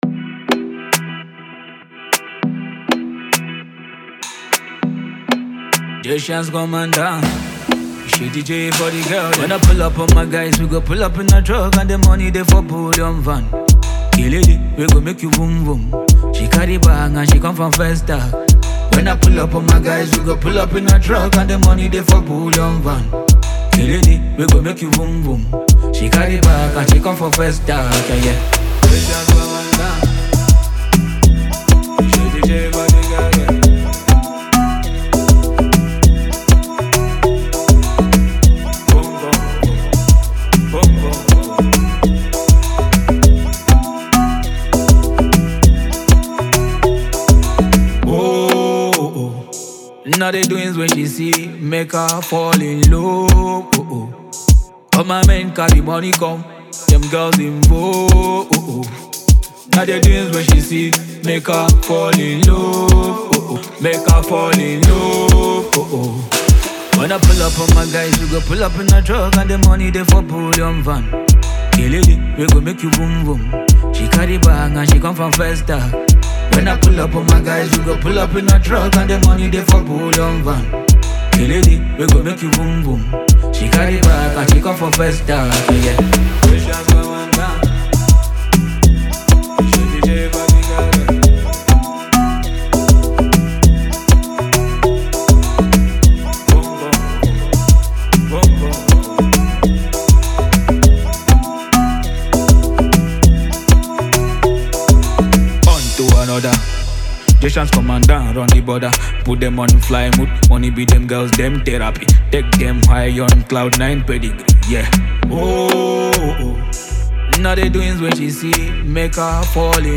keeps it simply groovy and expressive